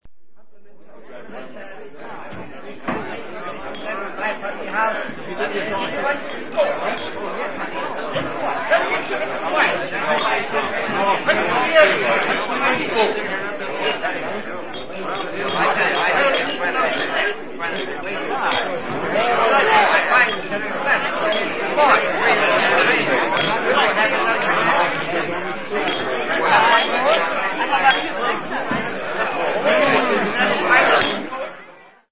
BAR CIUDAD INGLESA BARNOISE
Ambient sound effects
BAR_CIUDAD_INGLESA_BARNOISE.mp3